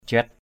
/ʥiat/ (t.) thuần chủng.